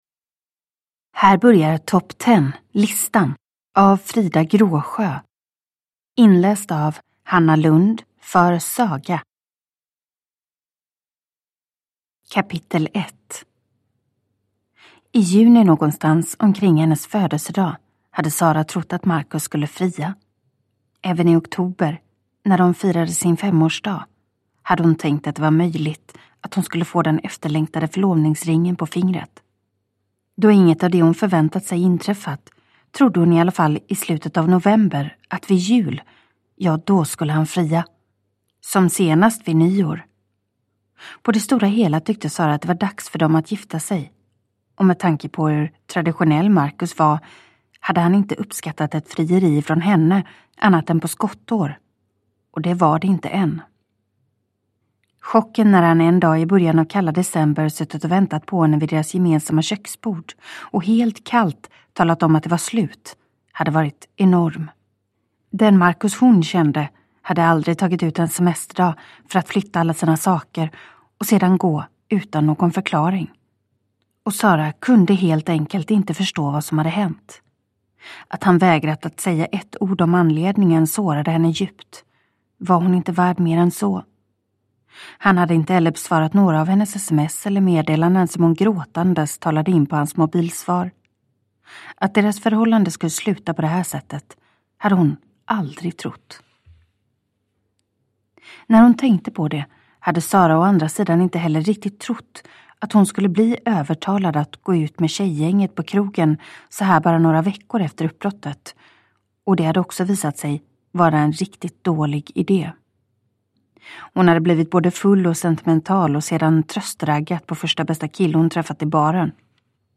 Top ten-listan / Ljudbok